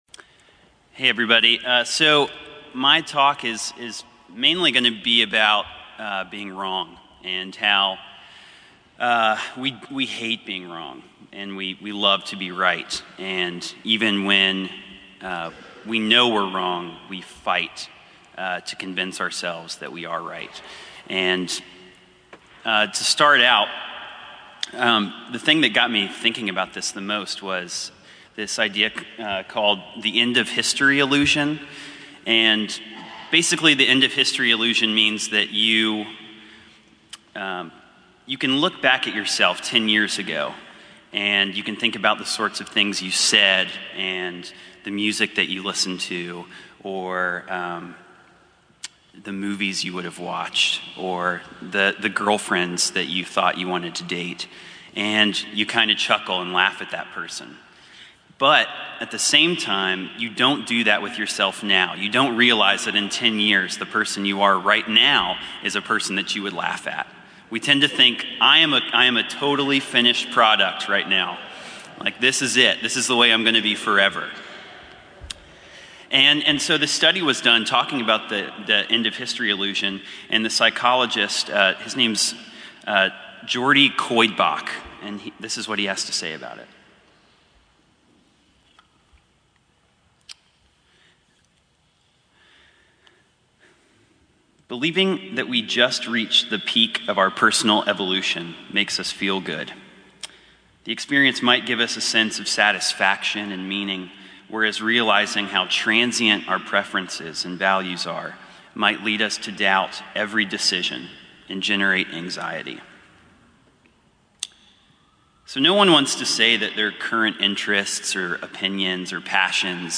Venue: 2016 NYC Conference